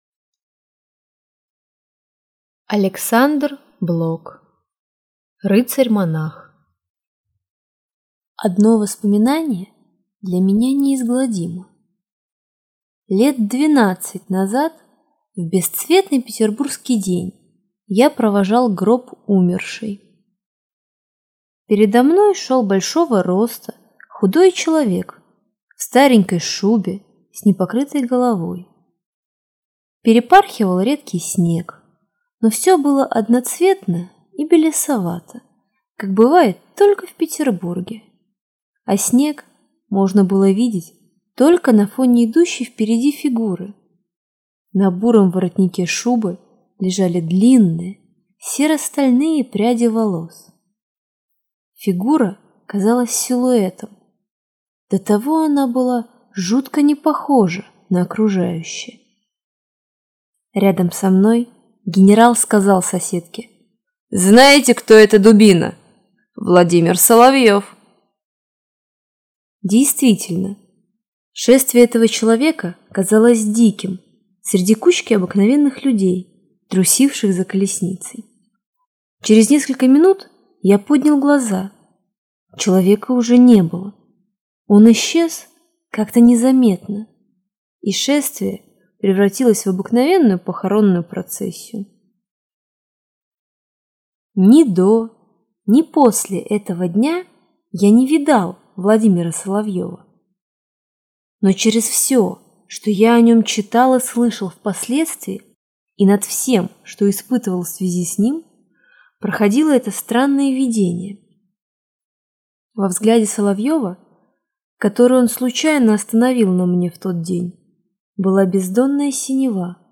Аудиокнига Рыцарь-монах | Библиотека аудиокниг
Прослушать и бесплатно скачать фрагмент аудиокниги